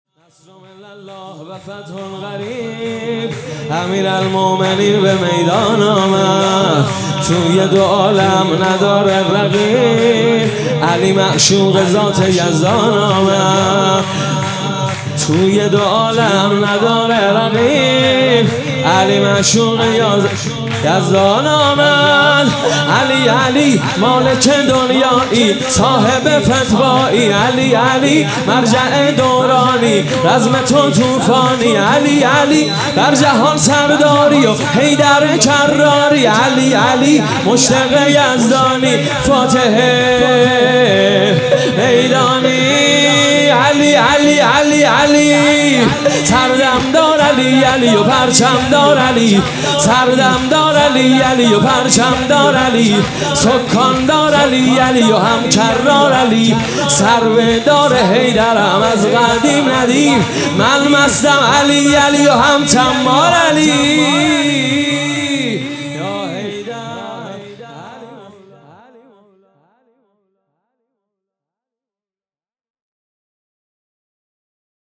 شور | توی دو عالم نداره رغیب
شب ولادت امیرالمؤمنین حضرت علی